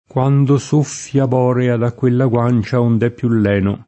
kU#ndo S1ffLa b0rea da kkU%lla gU#n©a ond $ ppLu ll$no] (Dante) — lene, forma dòtta, con -e- aperta da sempre; leno, forma pop. (lat. lenis con -e- lunga), attestata pure con -e- aperta dal ’500, ma certo in origine con -e- chiusa — cfr. allenare